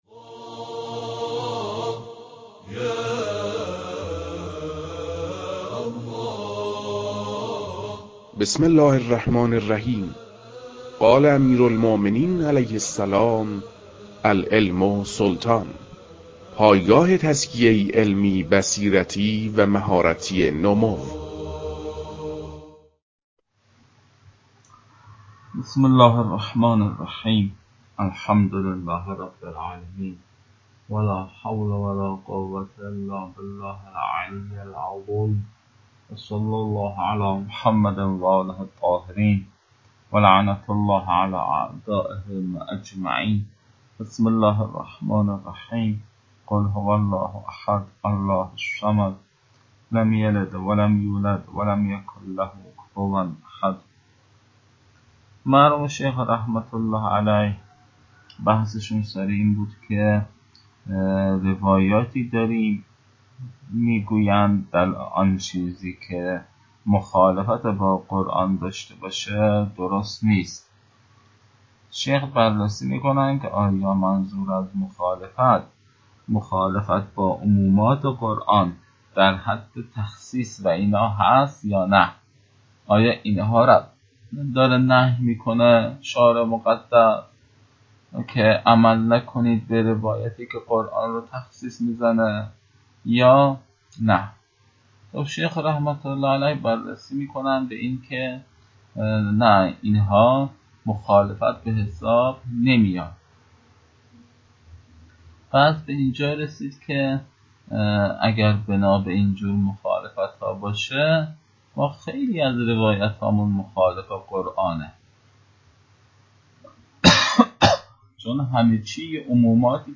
توصیه می‌شود قبل از این جلسه مباحث: 1) اختلاف در وضع الفاظ عبادات و معاملات در معنای اعم یا صحیح؛ 2) دلالت اسمای اجناس بر اطلاق و 3) تخصیص کتاب به خبر واحد پیش‌مطالعه گردد. (کیفیت صوت به‌دلیل بیماری استاد کمی ضعیف است.)